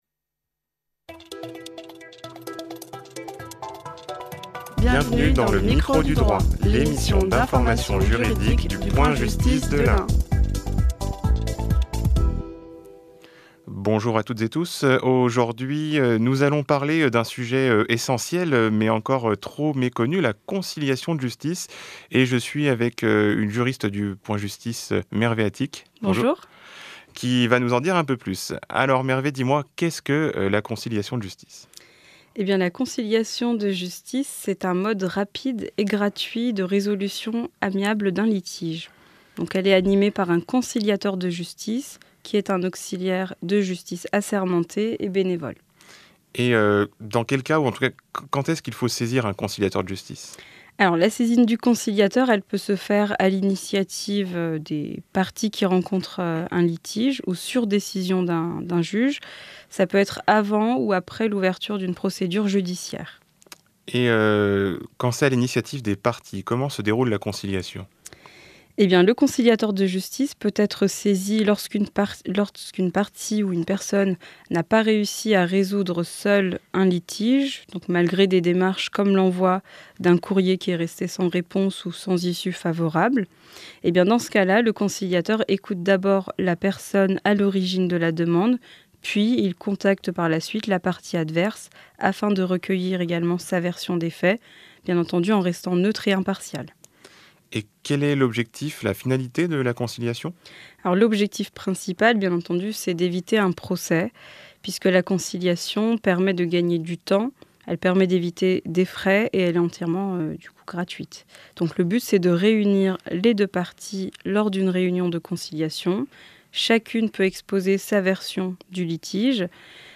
Les juristes du point-justice de l'Ain vous informent sur la conciliation de justice, son rôle et son déroulement.